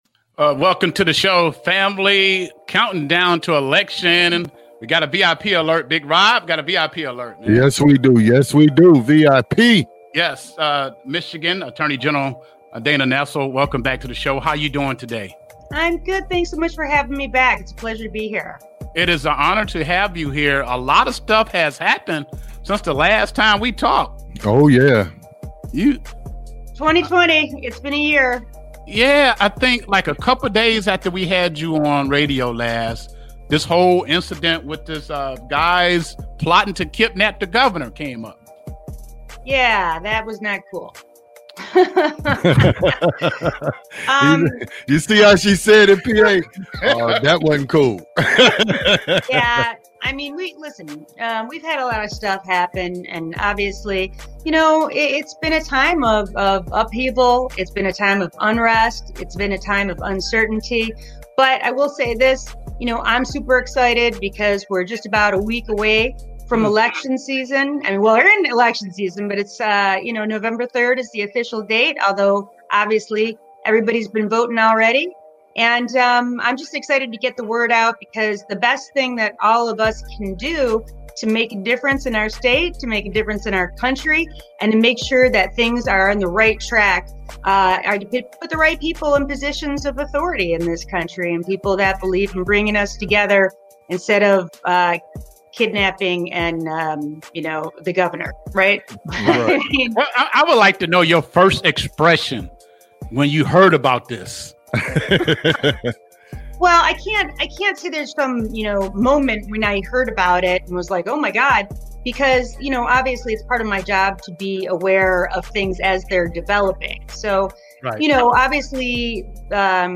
Interview with Attorney General Dana Nessel